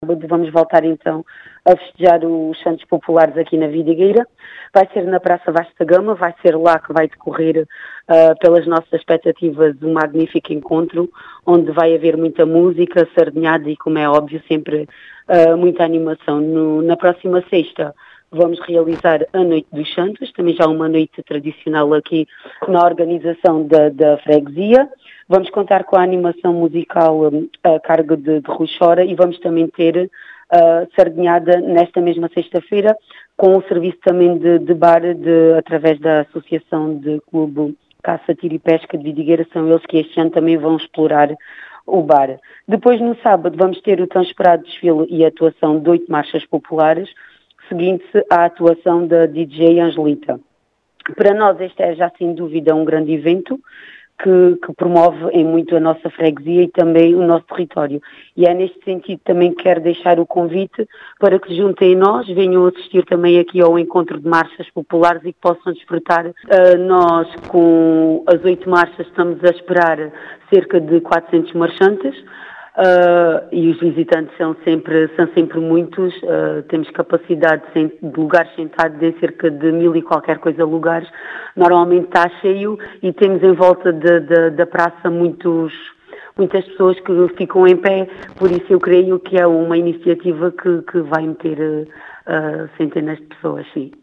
As explicações são da presidente da junta de freguesia de Vidigueira, Carla Penas, que aguarda centenas de visitantes e cerca de 400 marchantes a participar.